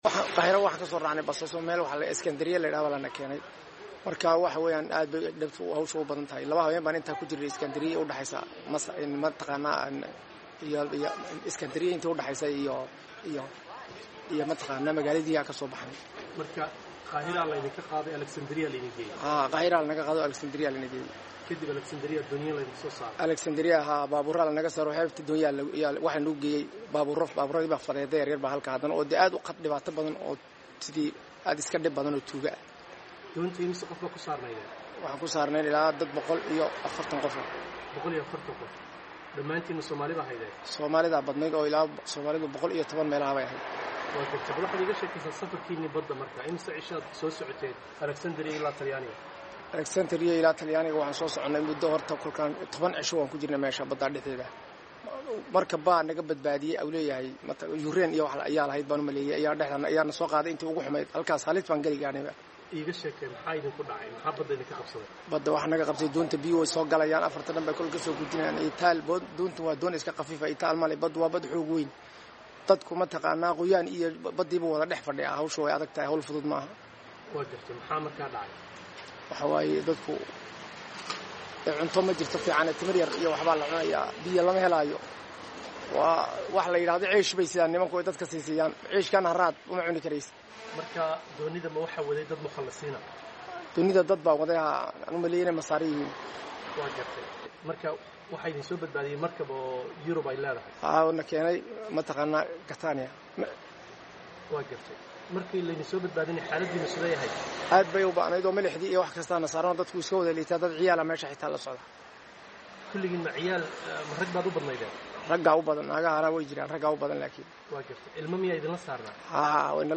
Wareysi: Oday Tahriibayay oo Talyaaniga Yimid